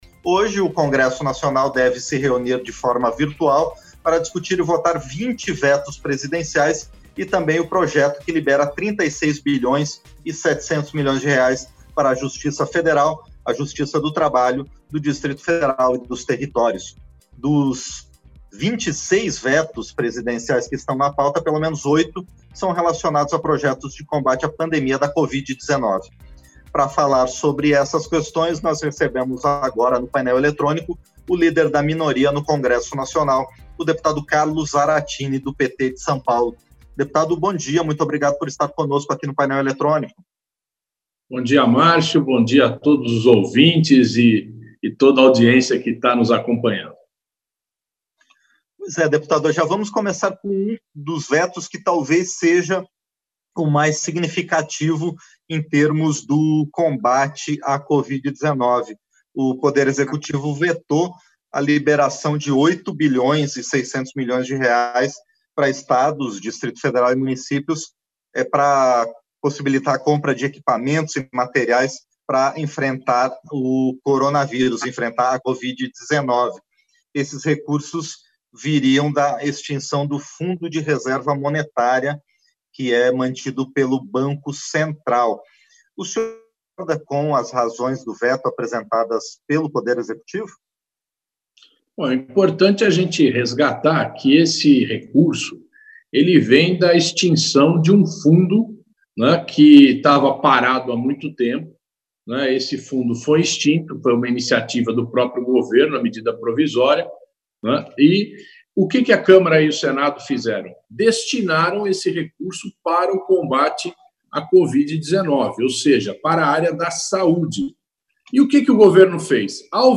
Entrevista - Dep. Carlos Zarattini (PT-SP)